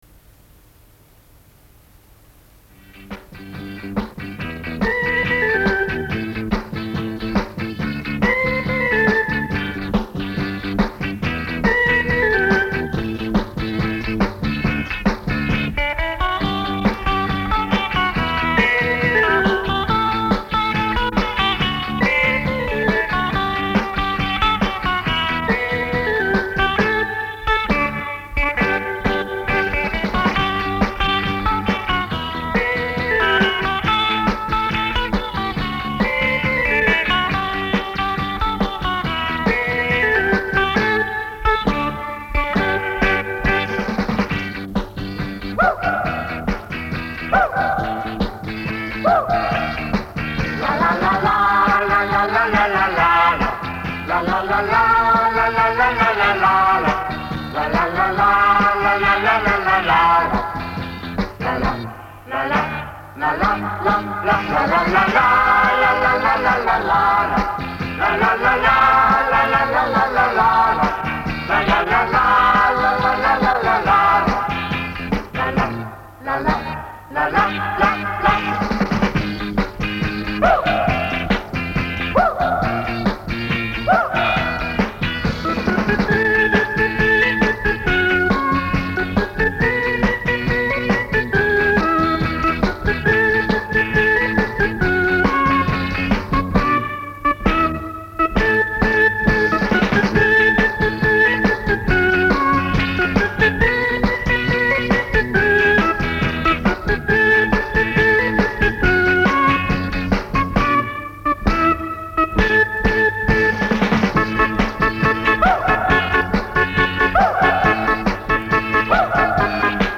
Похоже, это инструментал,который значится в моём поиске. Я как-то выкладывал магнитозапись с радио на КПЗ.